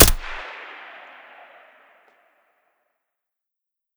shoot.ogg